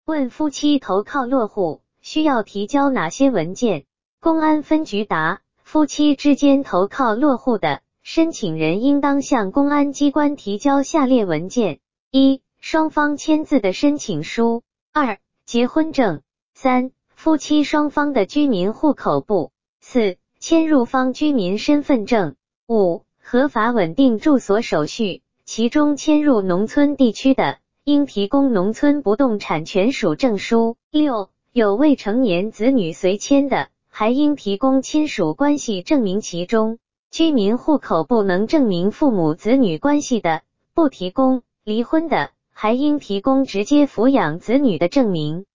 语音播报